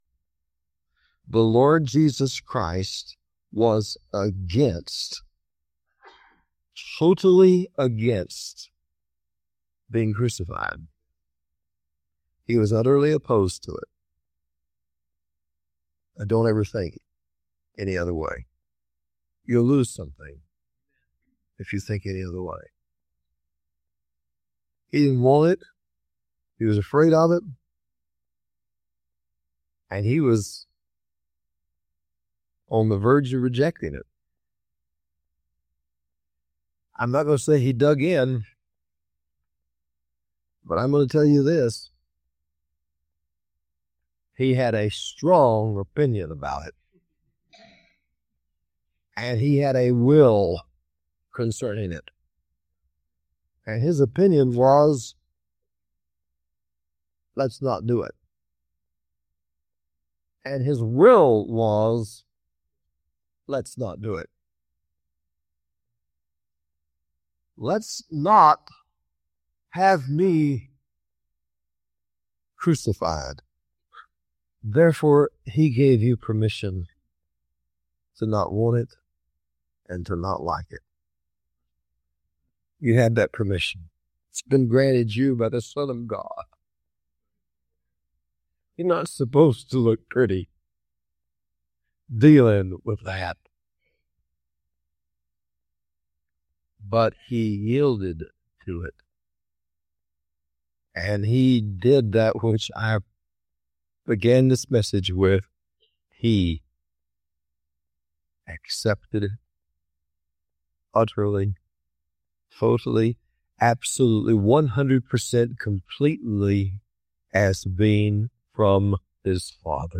A powerful teaching on yielding to crucifixion, forgiveness, and entering resurrection life after being wounded by Christians.